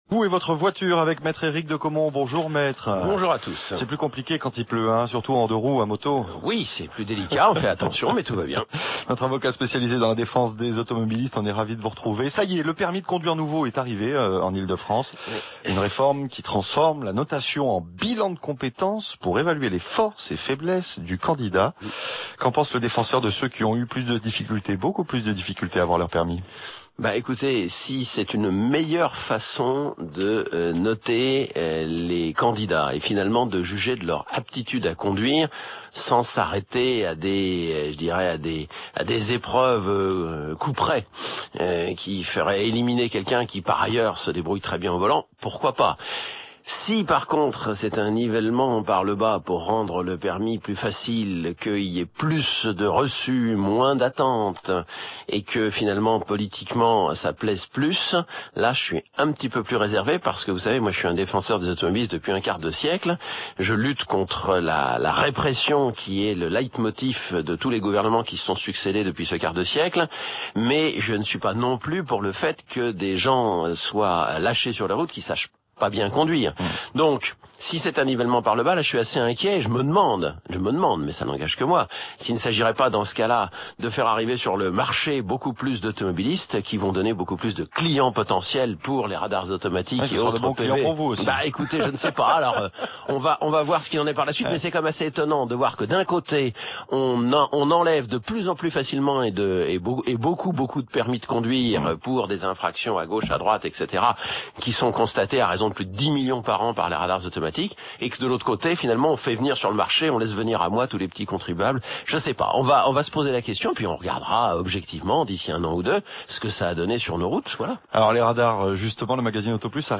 répond aux auditeurs de France Bleu dans l’émission « Les Experts » sur France Bleu le 07/04/2010